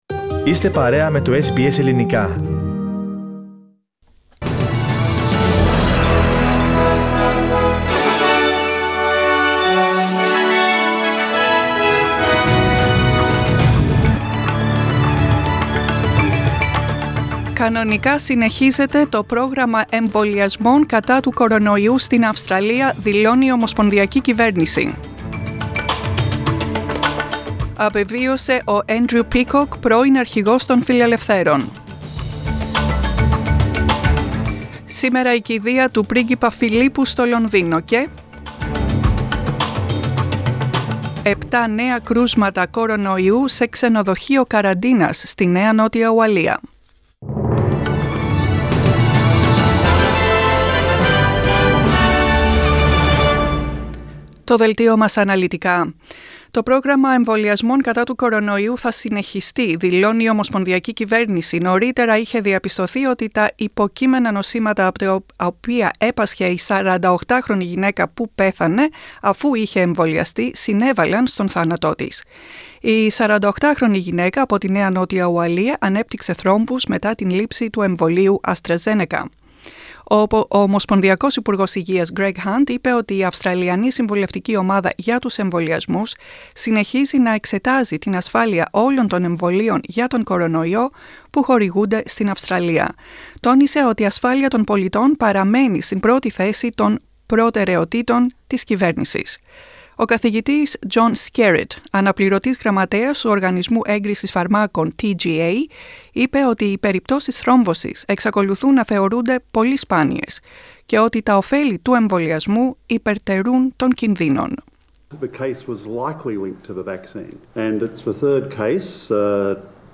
The main bulletin of the day from the Greek Program.